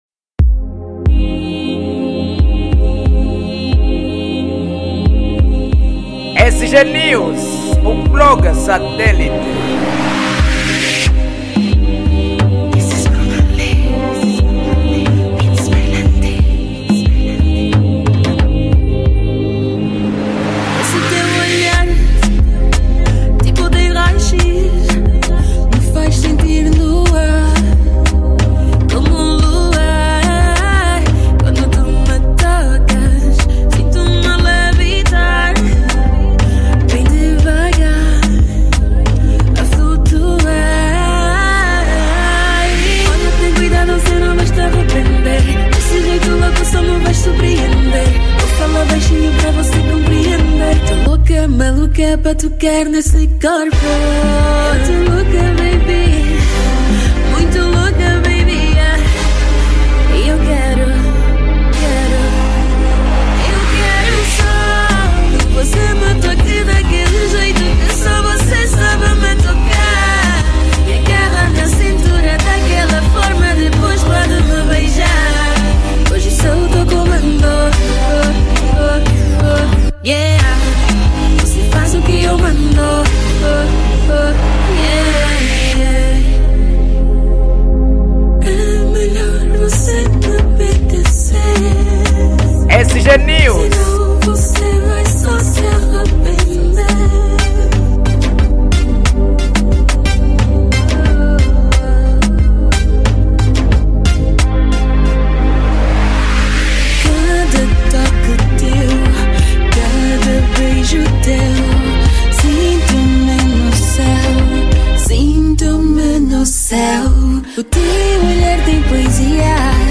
Género : Zouk